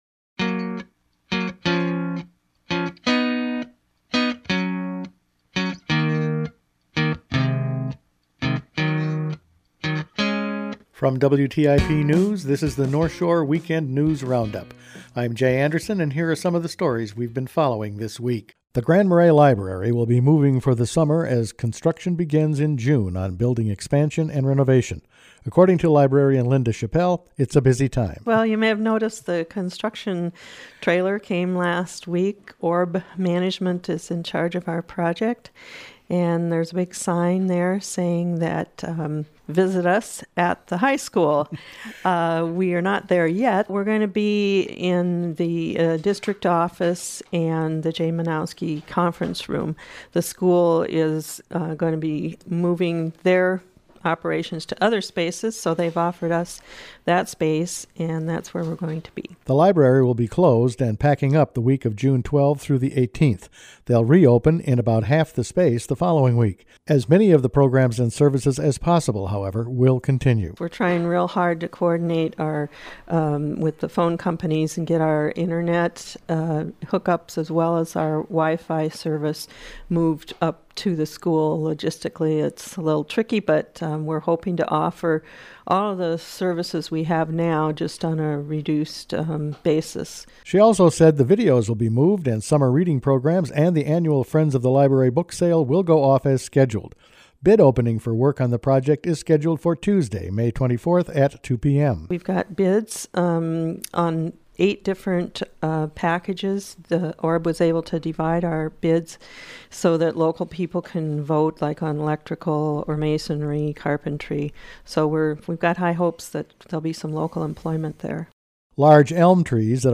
Weekend News Roundup for May 28
Each weekend WTIP news produces a round up of the news stories they’ve been following this week. A next step for the community center, the end of the legislative session and moving the public library were all in this week’s news.